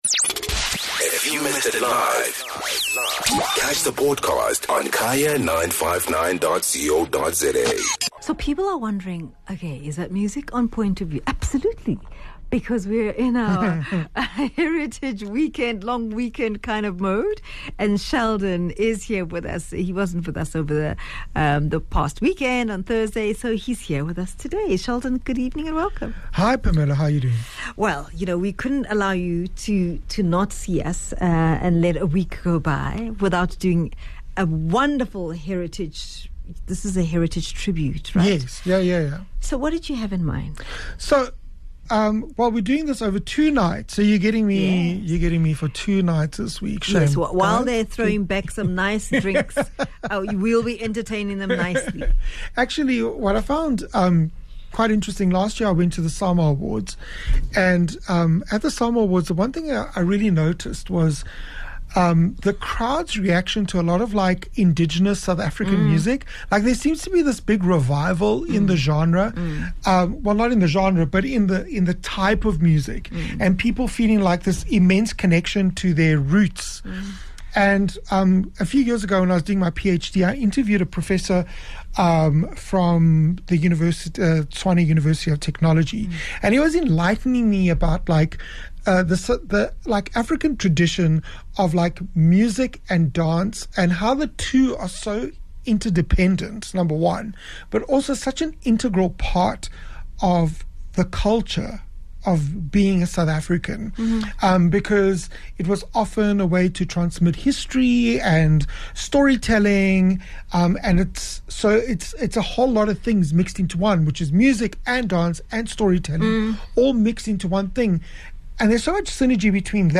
23 Sep Music Feature: SA Heritage